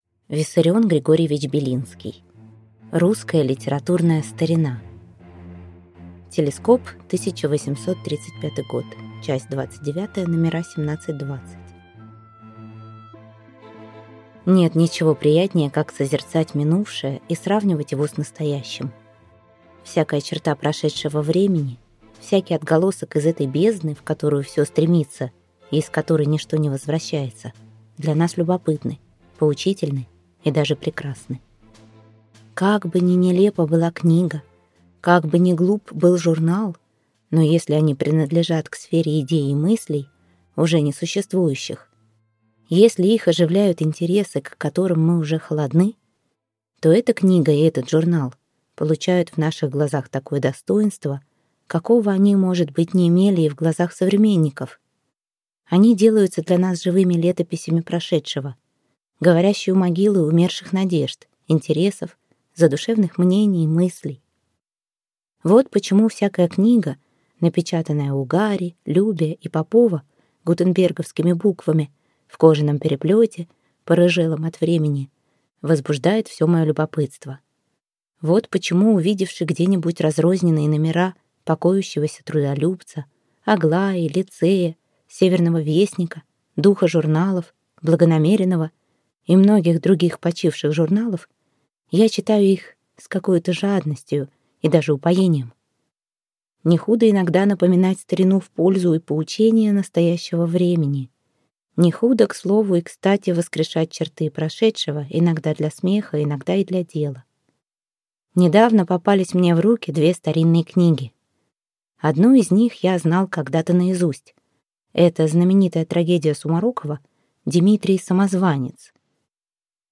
Аудиокнига Русская литературная старина | Библиотека аудиокниг